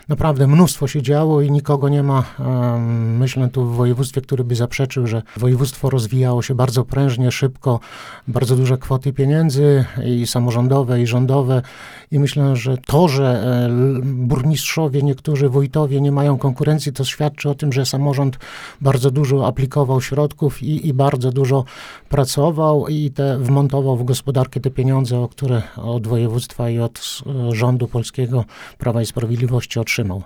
Umiarkowany optymizm ze wskazaniem na naszą formację – mówił na naszej antenie wicemarszałek województwa podlaskiego Marek Olbryś o odczuciach przed wyborami